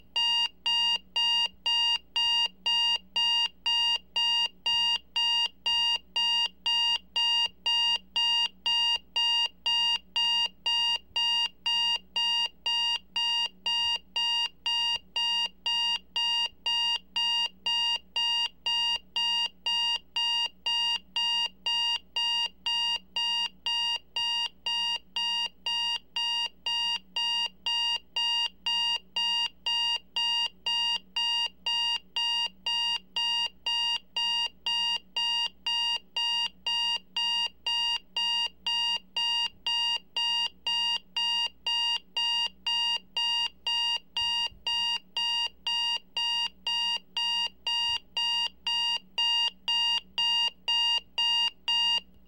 Digital alarm clock sound - Eğitim Materyalleri - Slaytyerim Slaytlar
digital-alarm-clock-sound